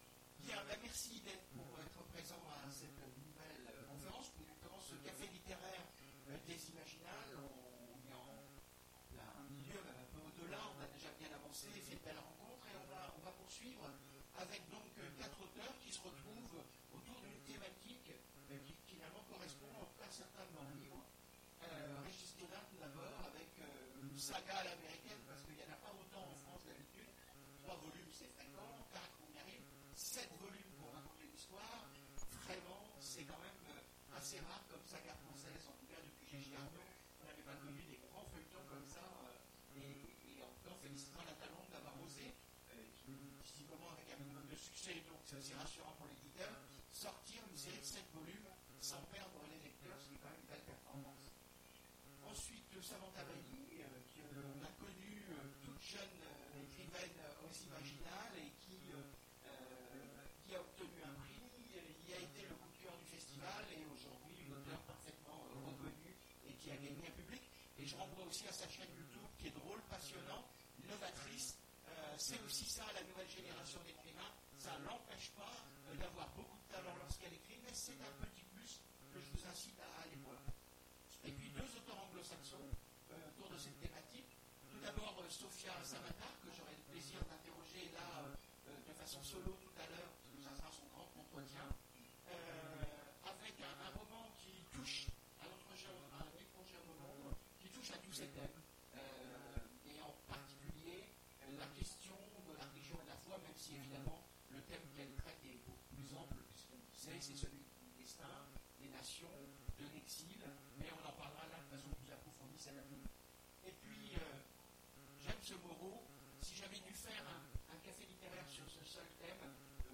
Imaginales 2017 : Conférence Religions et religieux… Fanatiques et hommes de paix ?